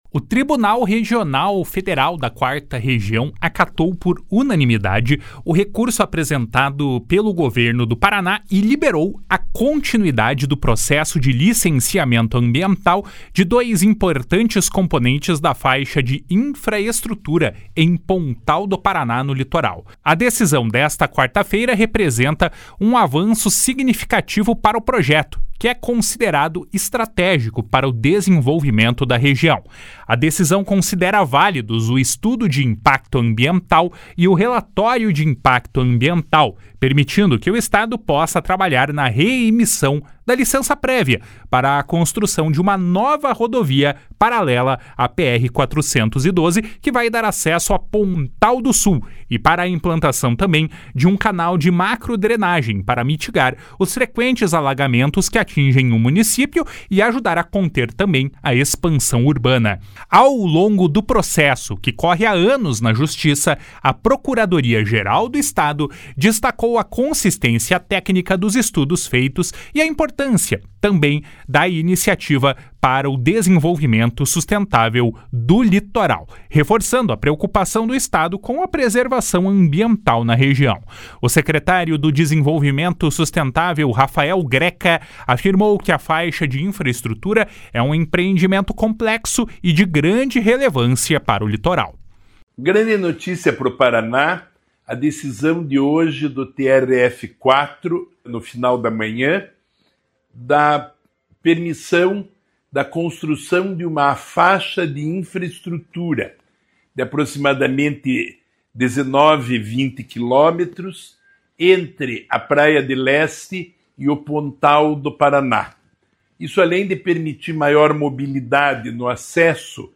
// SONORA RAFAEL GRECA //